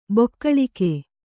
♪ bokkaḷike